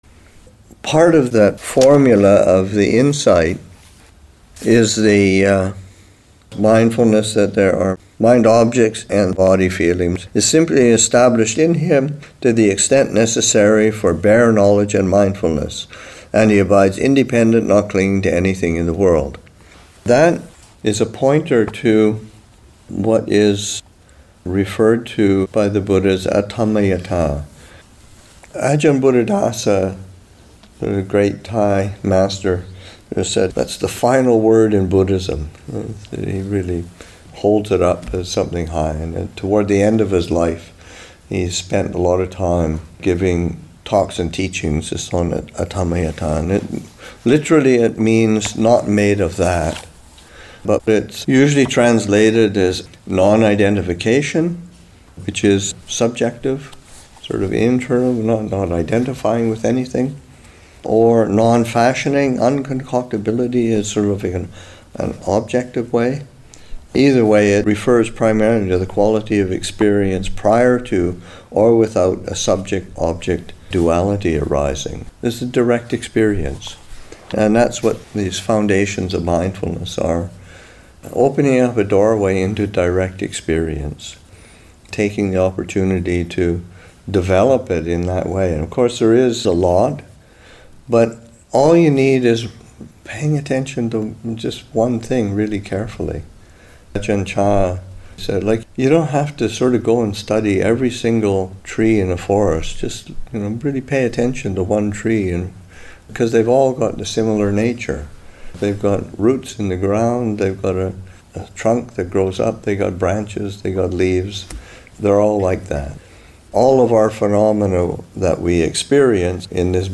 Kathina Q&A with the Chithurst Community [2025], Session 1, Excerpt 2